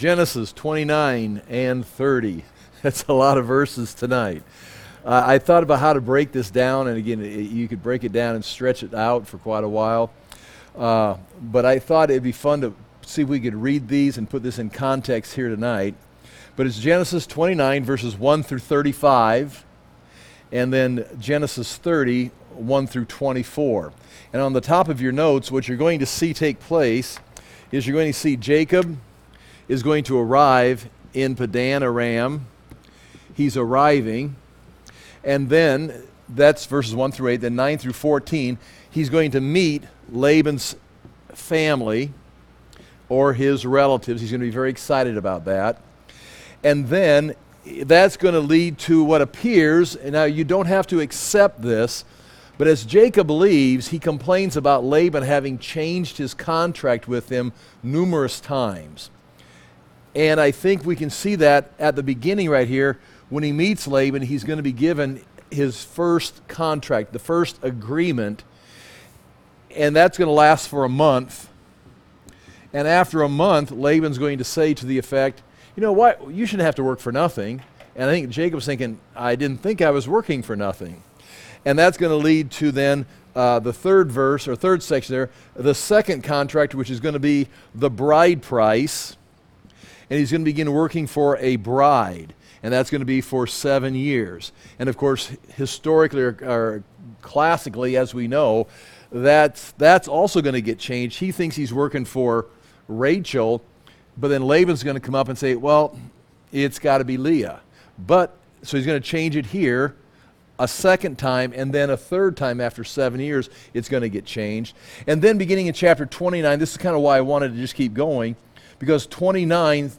Genesis - Verse by Verse Teaching in the Book of Genesis